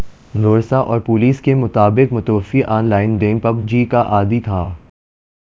Spoofed_TTS/Speaker_14/100.wav · CSALT/deepfake_detection_dataset_urdu at main